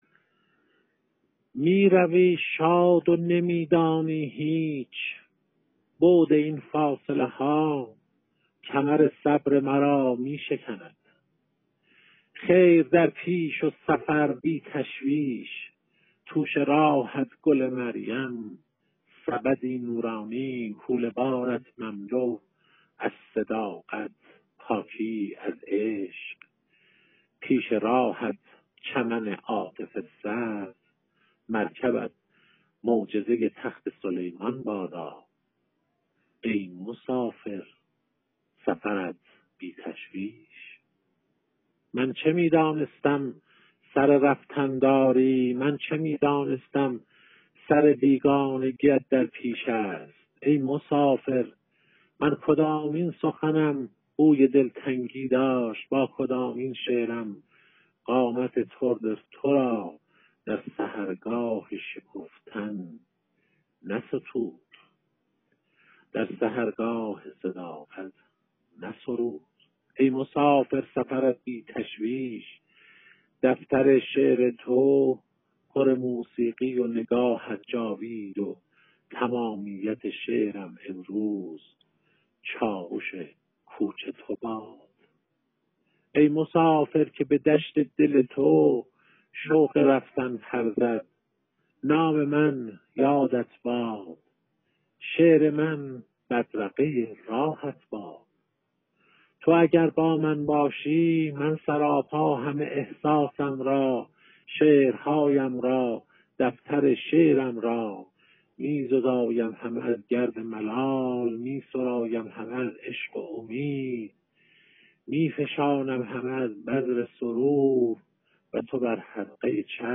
منظومه